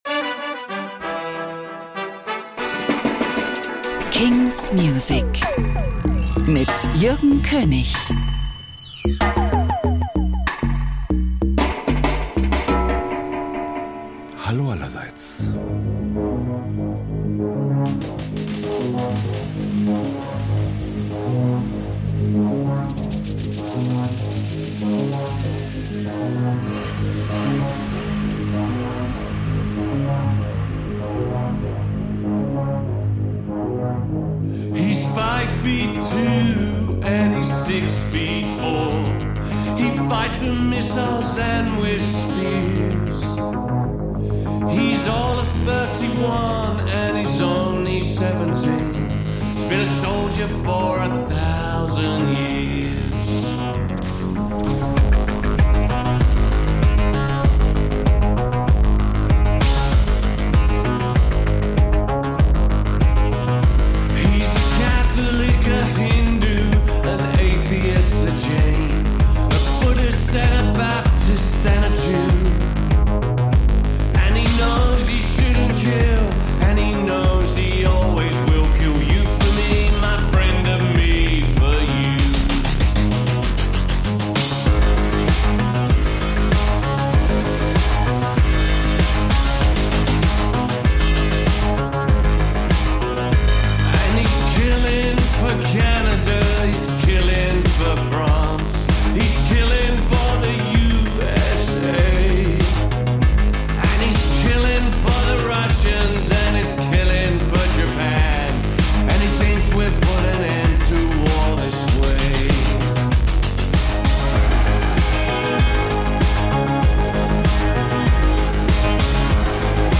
brandnew indie & alternative releases